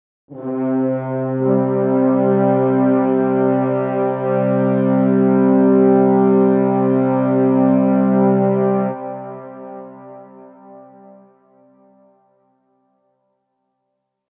Soft Battle Horn Sound Effect
Description: Soft battle horn sound effect. Gentle and distant horn call with an ancient tone.
Soft-battle-horn-sound-effect.mp3